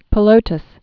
(pə-lōtəs, pĭ-lôtäs)